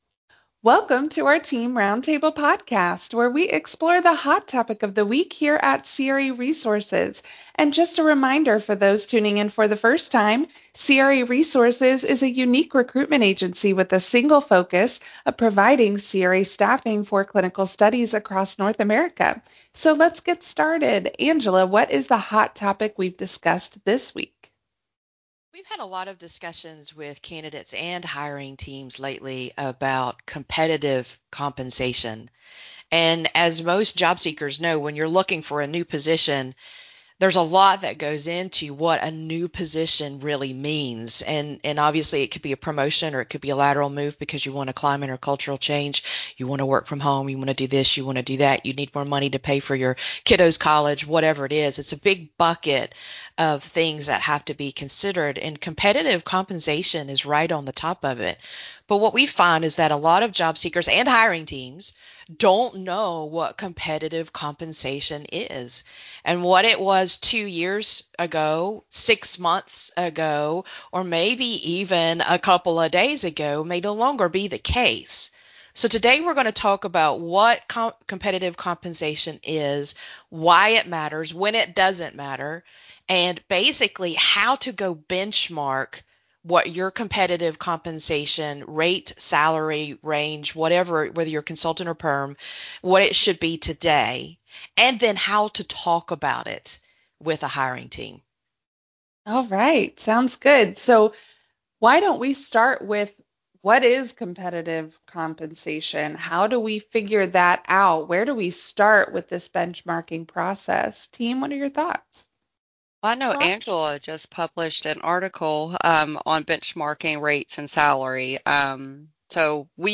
Roundtable: Are You Receiving Fair Compensation?